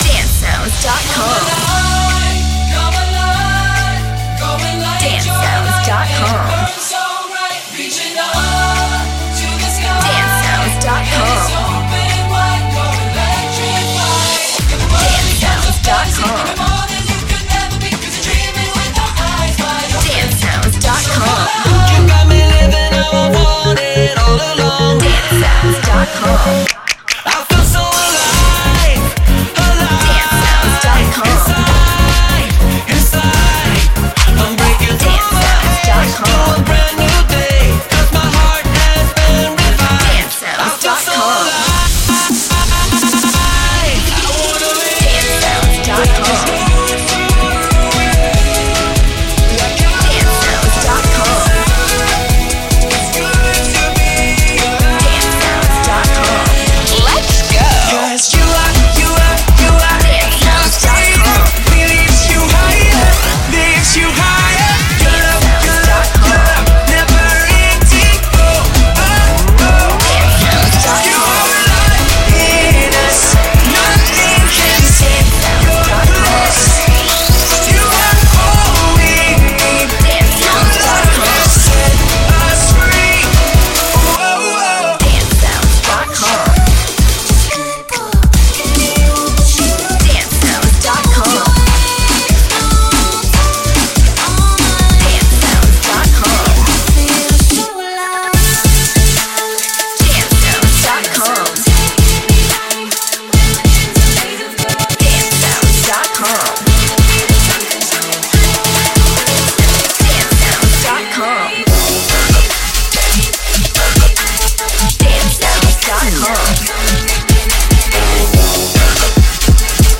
Premade Dance Music Mix